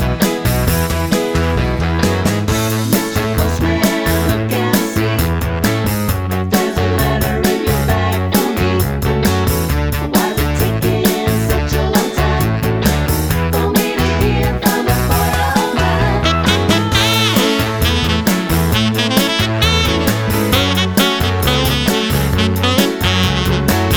Pop (1970s)